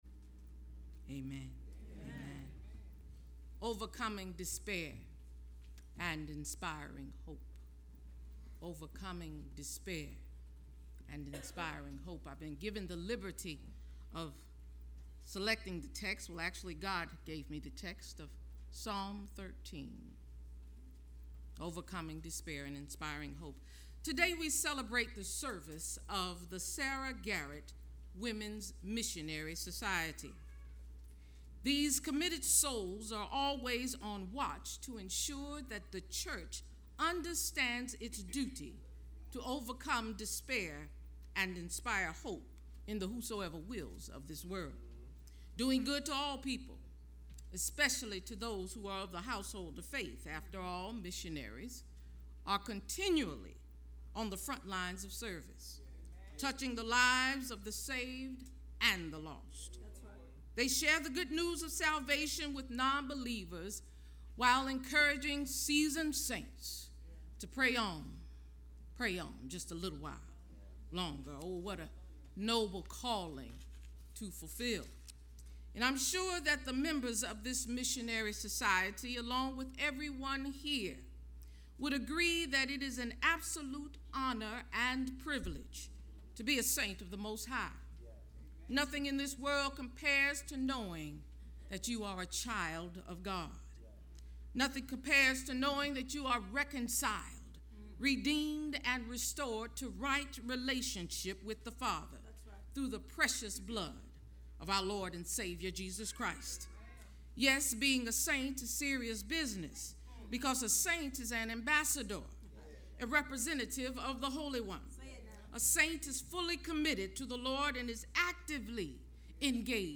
Sermon
LMP_Sermon_5-17-15.mp3